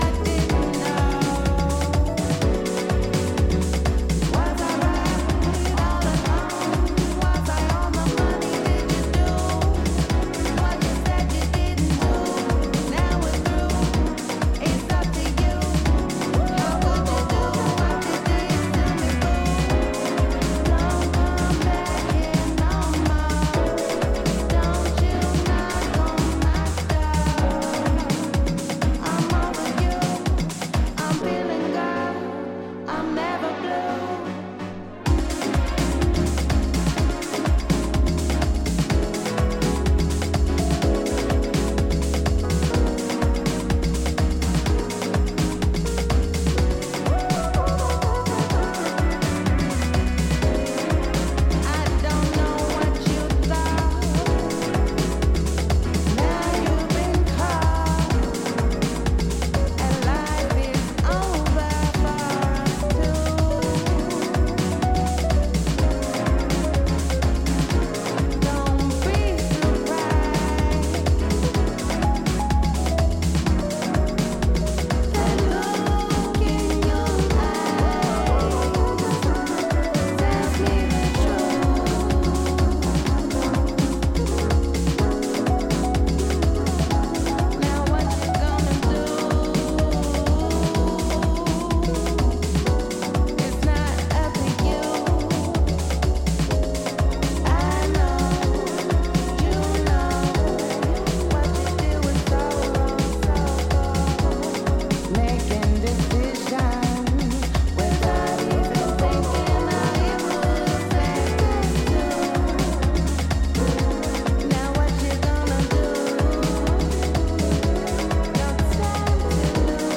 vocal mix